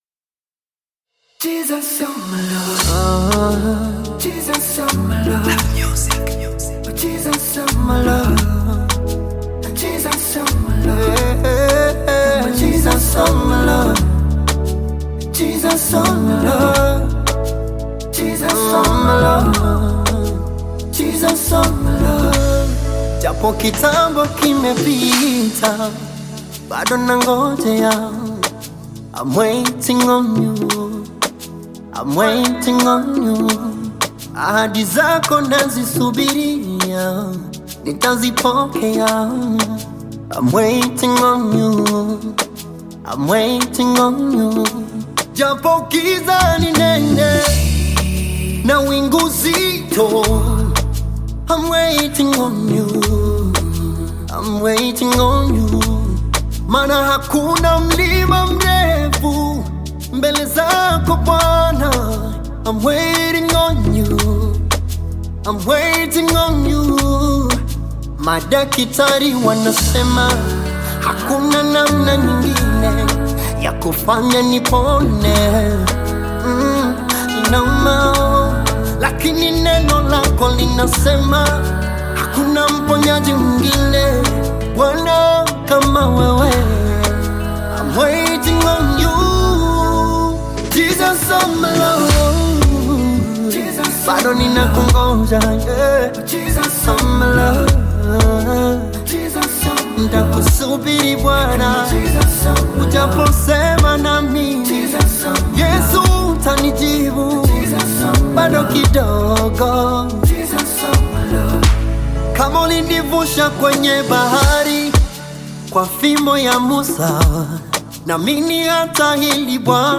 Gospel music track
Tanzanian gospel artist, singer, and songwriter